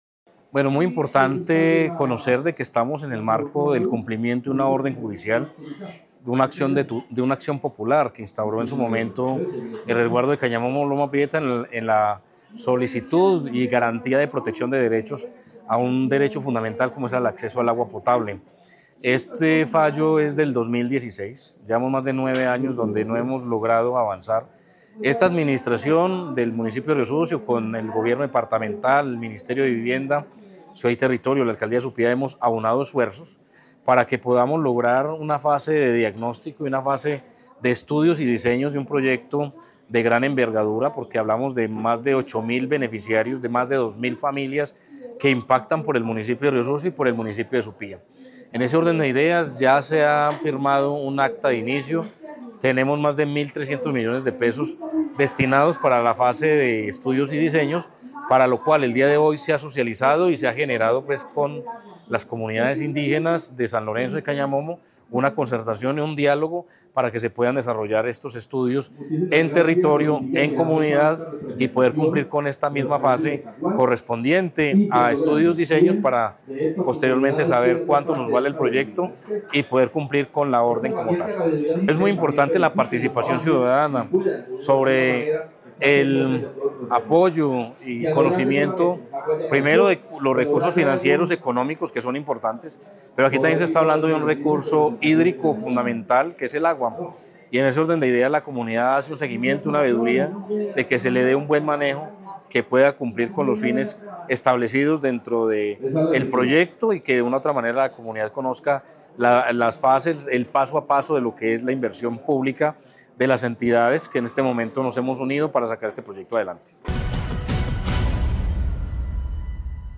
Alcalde de Riosucio, Abel David Jaramillo Largo.